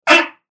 PLAY Angry Fart
fart-angry.mp3